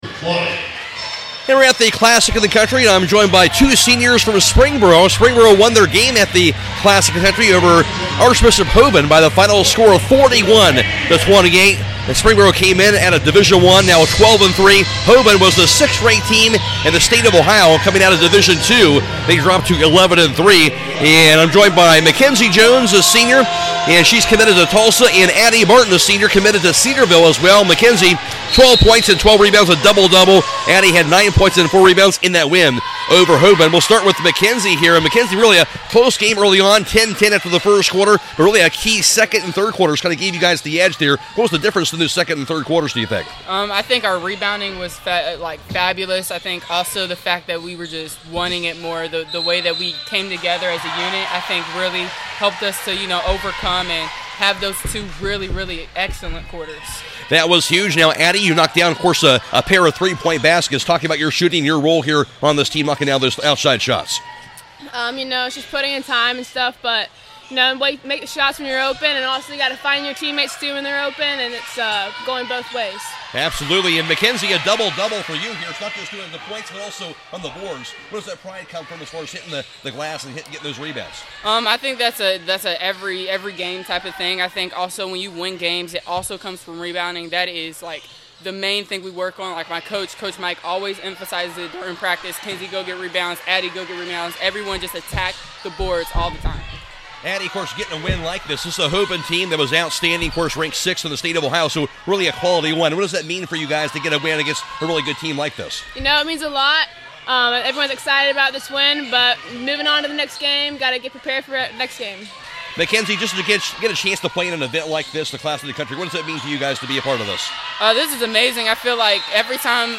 2026 CLASSIC – SPRINGBORO PLAYER INTERVIEWS